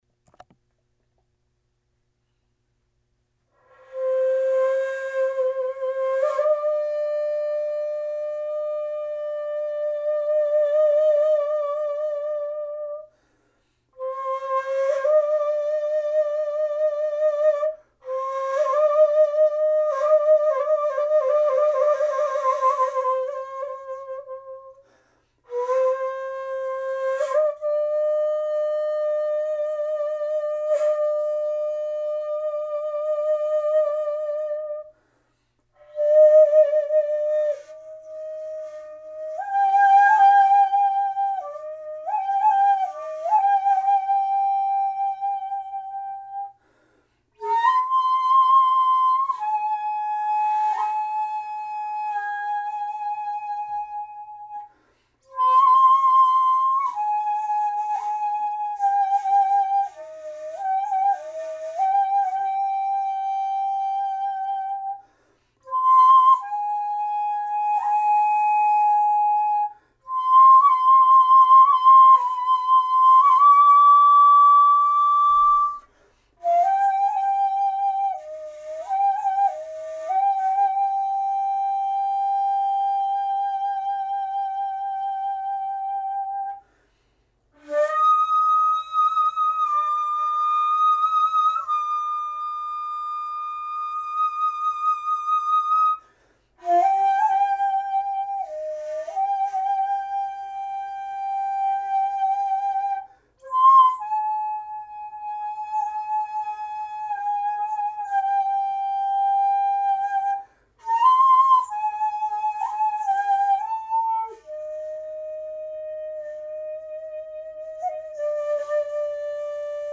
今回、「戯れ吹き」として「恋慕流」の一部分を演奏していますが、その箇所の「ゴマ点」を意図的に消してみました。
そして江戸時代のメモ程度の楽譜のようにして割合自由に吹いてみました。